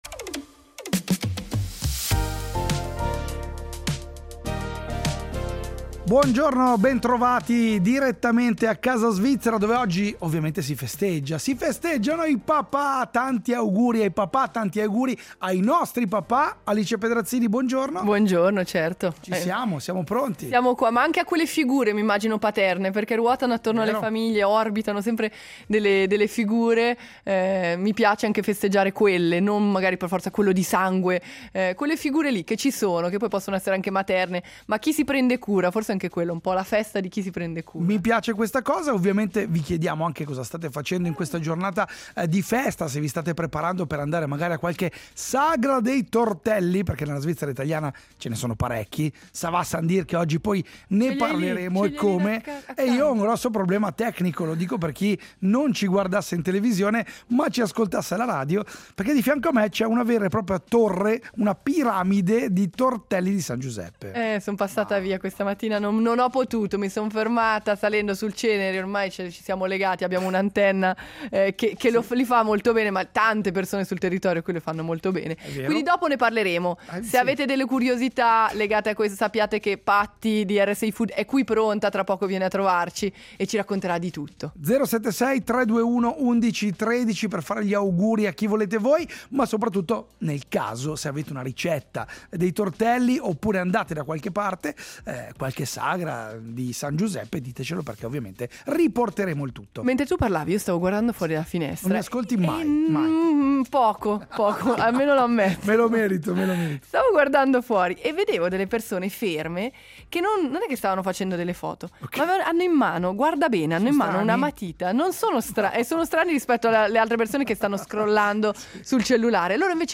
In città, ma con un silenzio diverso: non il rumore delle macchine, ma il fruscio della carta e il graffio leggero delle matite.